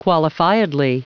Prononciation du mot qualifiedly en anglais (fichier audio)